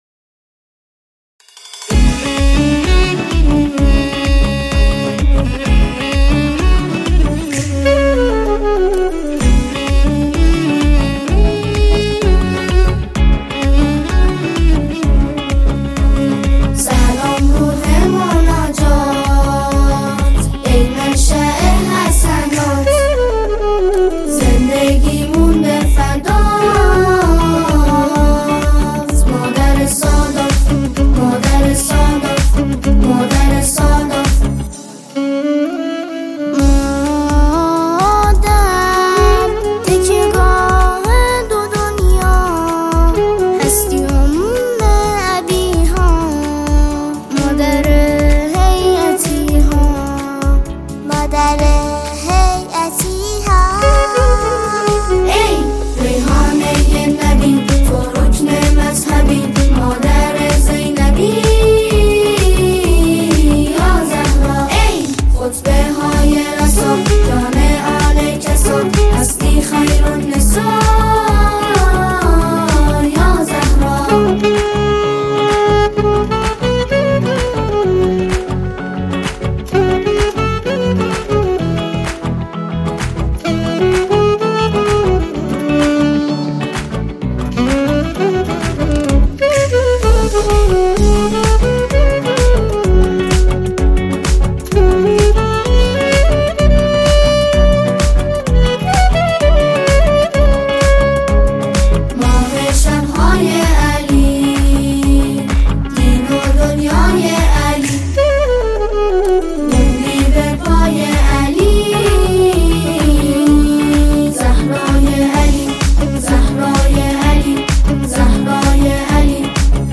ولادت حضرت زهرا (س)